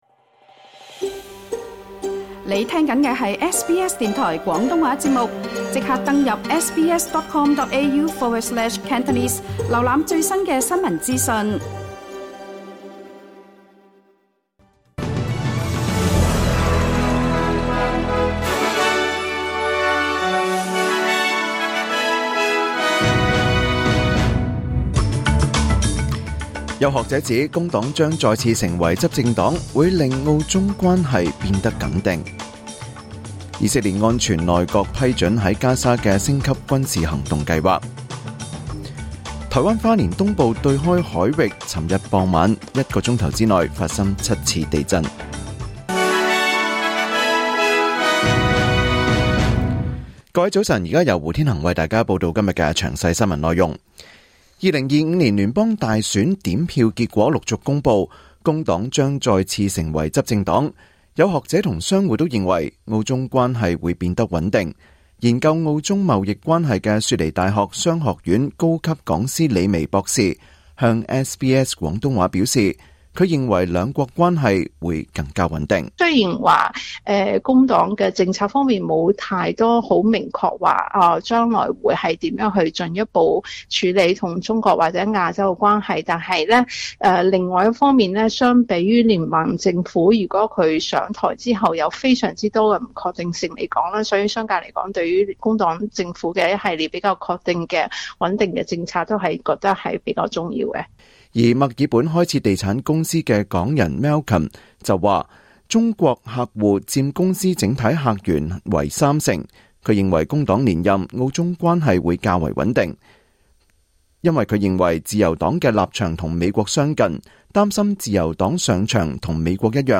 2025年5月6日SBS 廣東話節目九點半新聞報道。